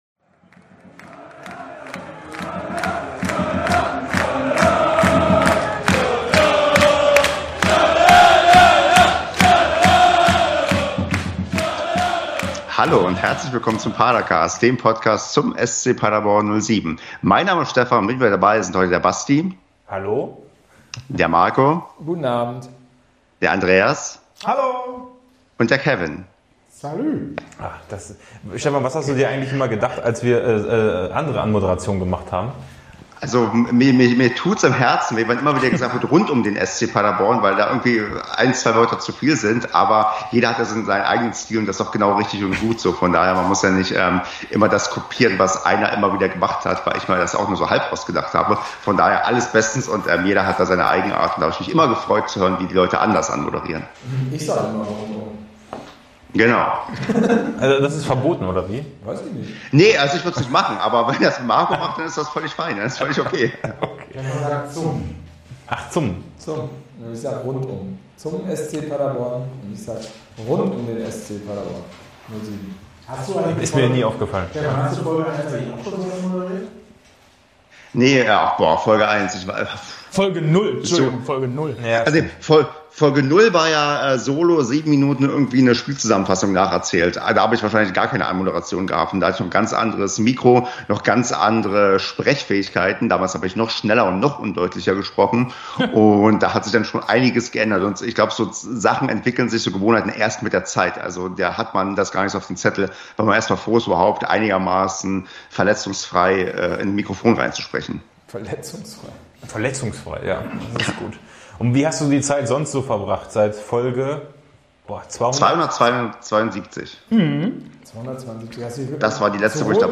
Entschuldigt den durchschnittlichen Ton, ab kommender Woche wieder wie gewohnt.